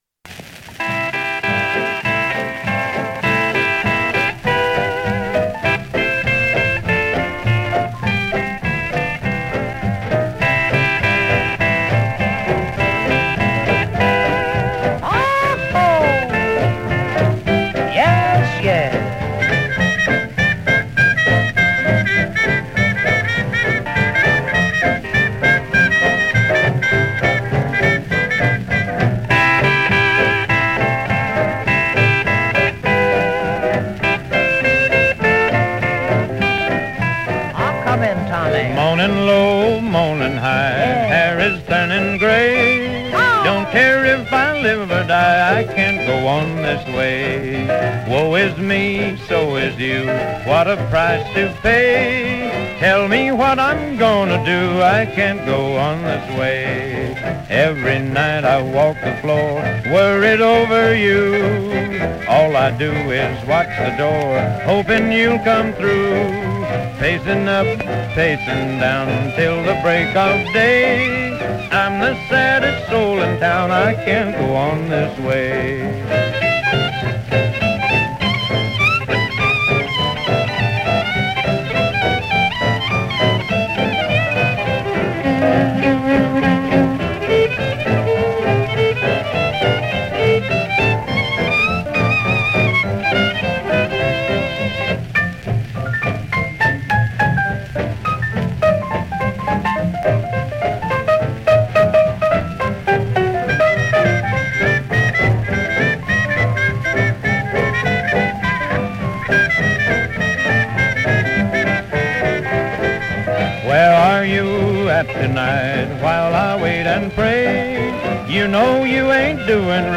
restored version